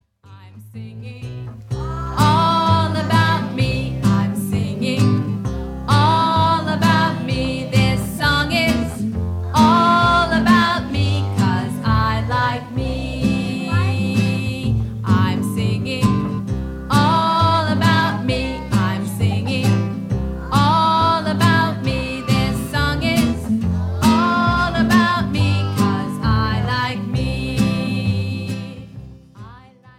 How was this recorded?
Recorded before an audience of children